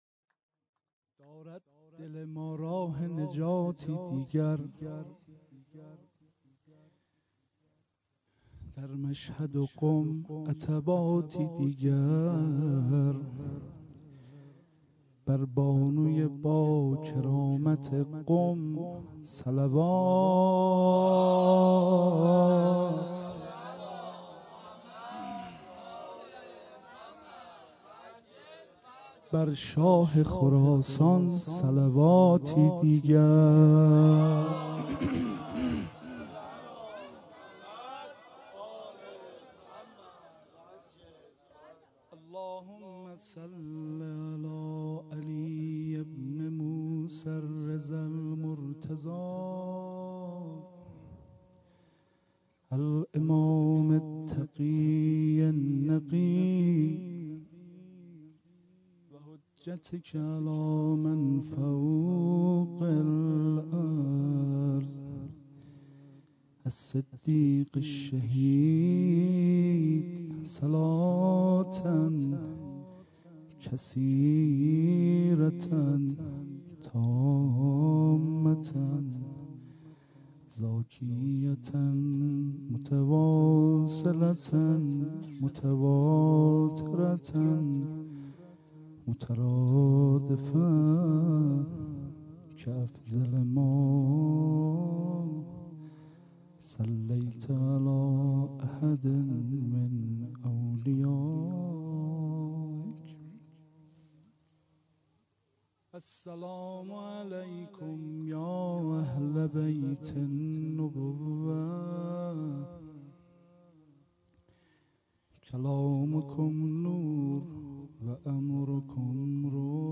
شعر «1»